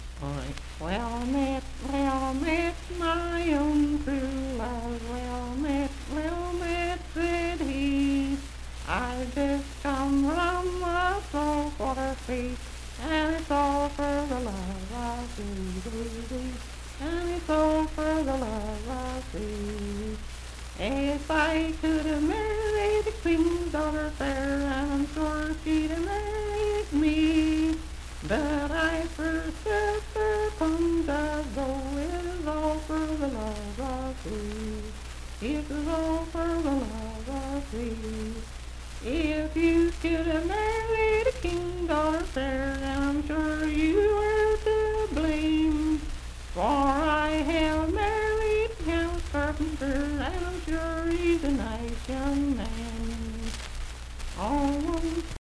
Unaccompanied vocal music
Voice (sung)
Hardy County (W. Va.), Moorefield (W. Va.)